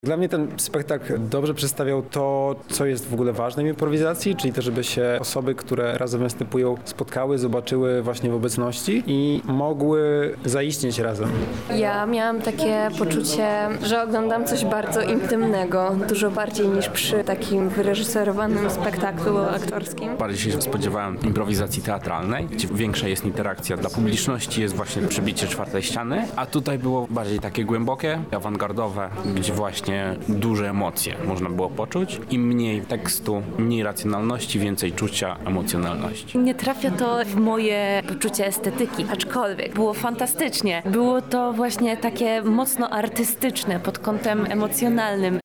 ImproSpace, relacja